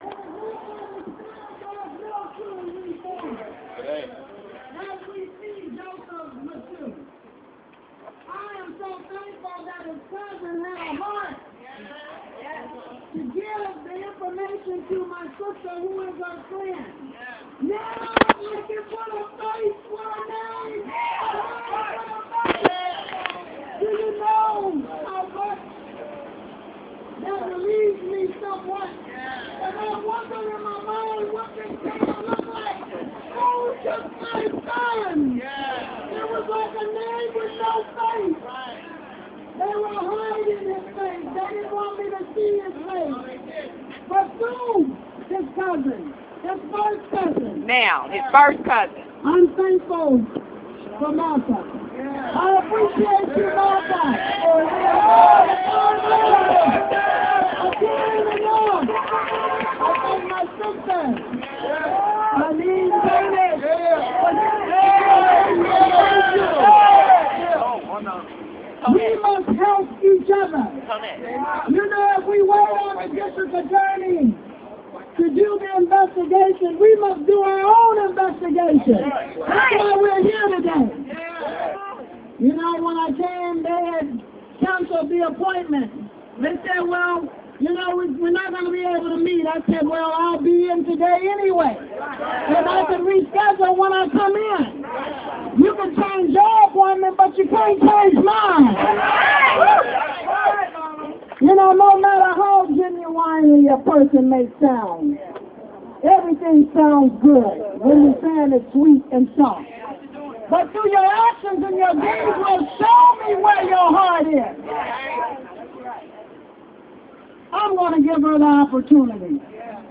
§rally outside courthouse